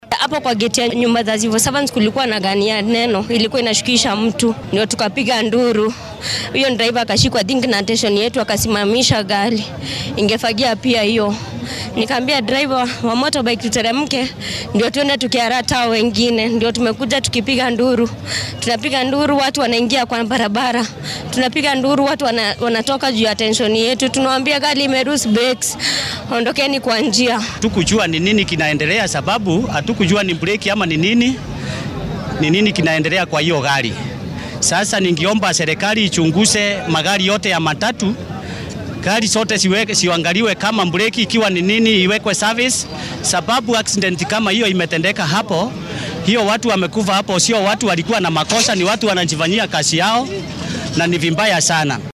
Goobjoogayaal-shilka-Embu.mp3